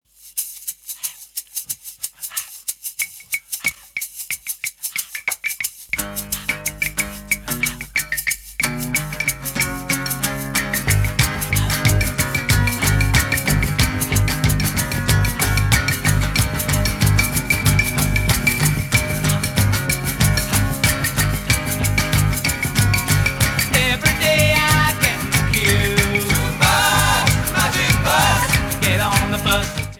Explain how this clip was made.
Mono) (Unedited version